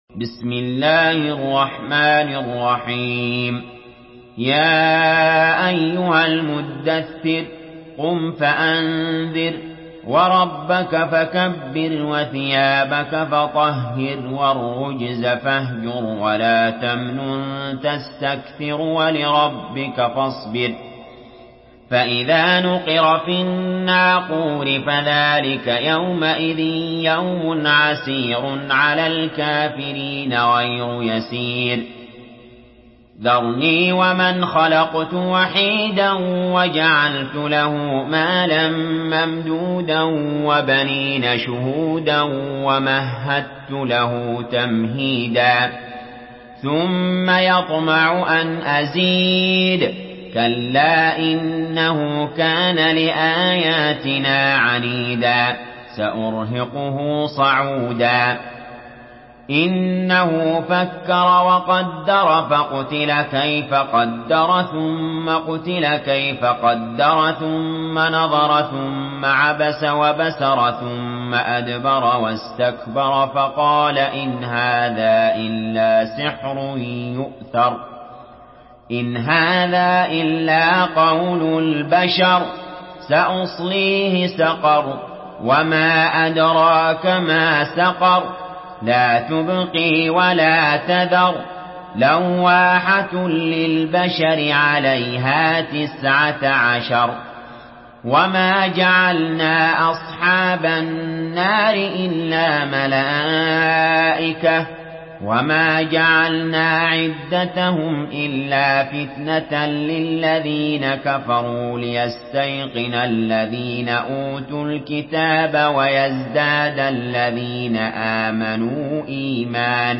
Surah المدثر MP3 by علي جابر in حفص عن عاصم narration.
مرتل